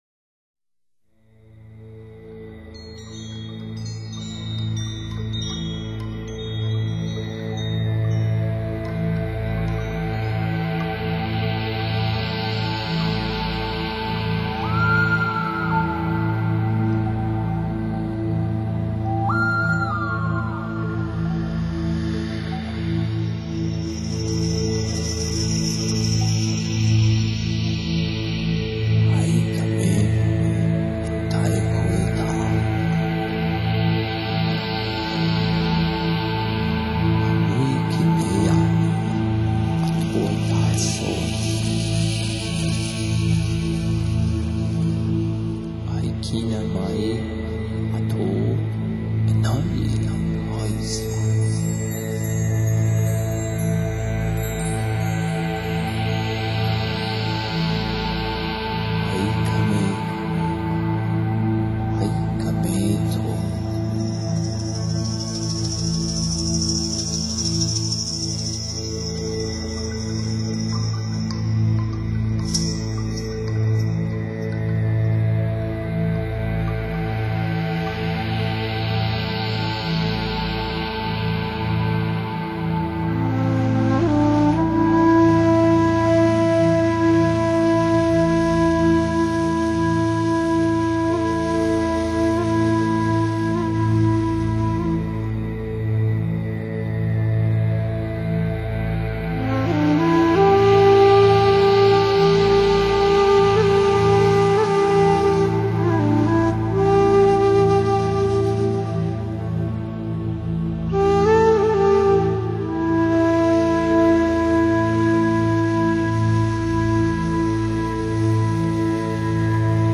竹笛、印第安排箫、轻柔的鼓声…，回旋出朴拙而轻松的原始氛围， 使人进入与天地交融的深沉宁静中，唤醒内在。